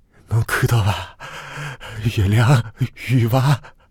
c02_疯子对话圆月亮1.ogg